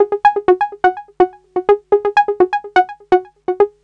cch_synth_notesyn_wet_125_Gb.wav